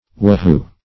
whahoo - definition of whahoo - synonyms, pronunciation, spelling from Free Dictionary Search Result for " whahoo" : The Collaborative International Dictionary of English v.0.48: Whahoo \Wha*hoo"\, n. (Bot.) An American tree, the winged elm.